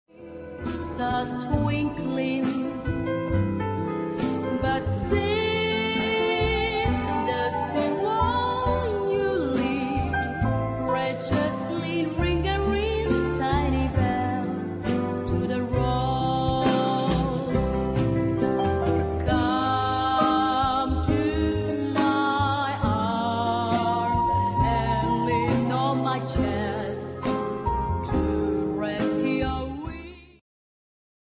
ジャンル Jazz
Progressive
癒し系
東洋とも西洋とも言えない新しい宇宙サウンドを聴かせてくれる